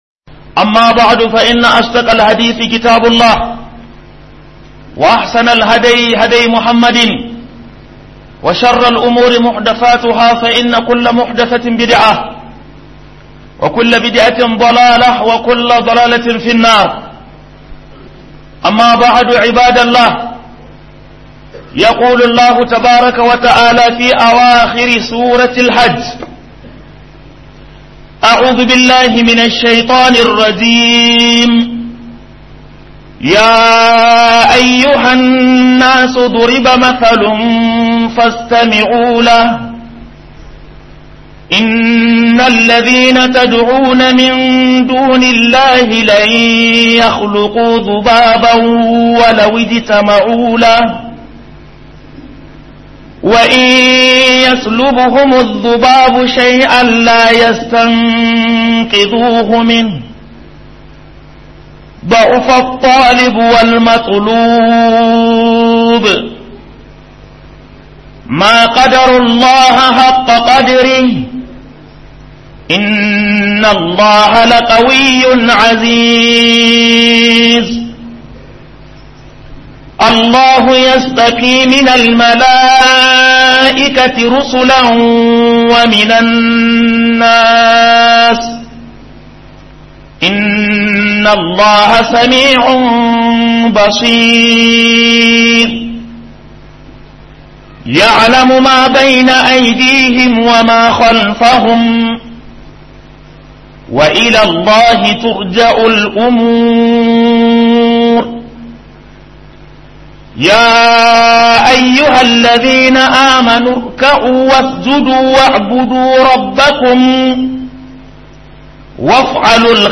Book HUDUBA